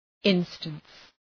{‘ınstəns}